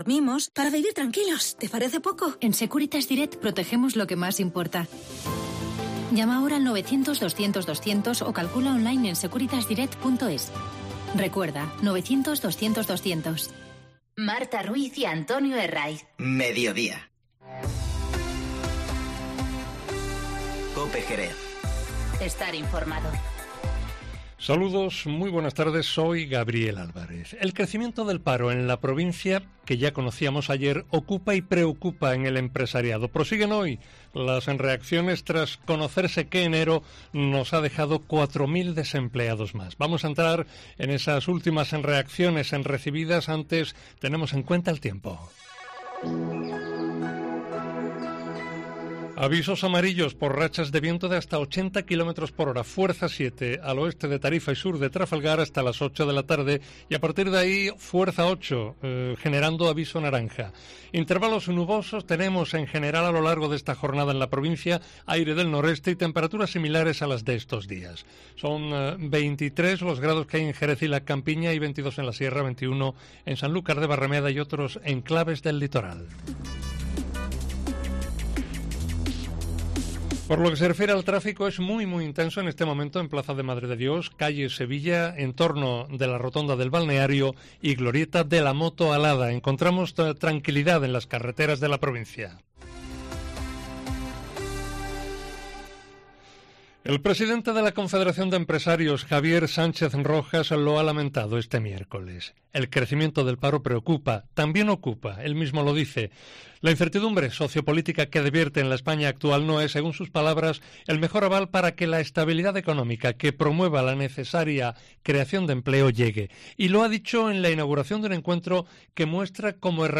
Informativo Mediodía COPE en Jerez 05-02-20